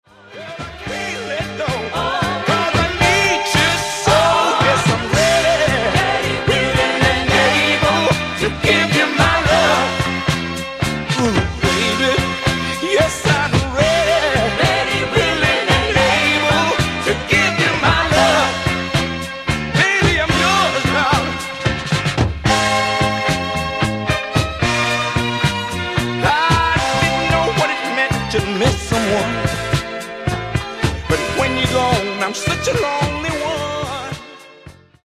Genere: Disco | Soul |